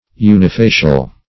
Unifacial \U`ni*fa"cial\, a. [Uni- + facial.]